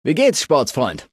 Datei:Maleadult01default convandale hello 0002ec9c.ogg
Fallout 3: Audiodialoge